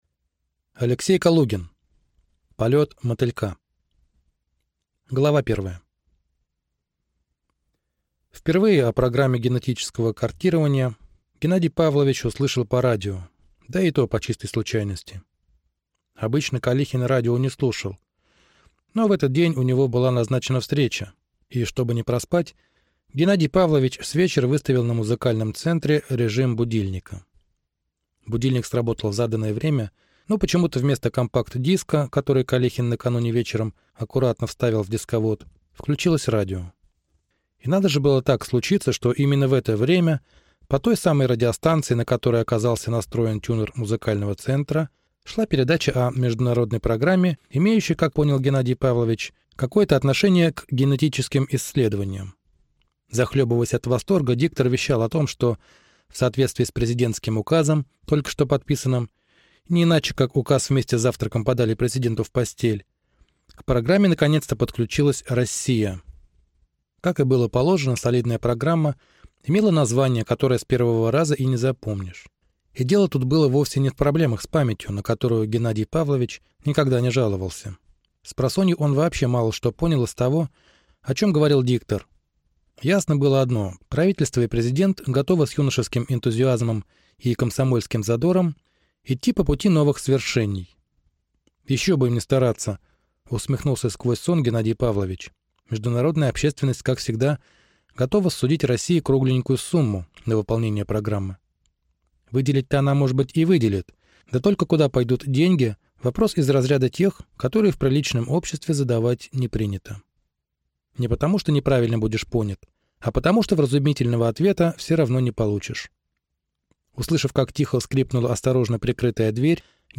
Аудиокнига Полет мотылька | Библиотека аудиокниг